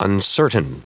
Prononciation du mot uncertain en anglais (fichier audio)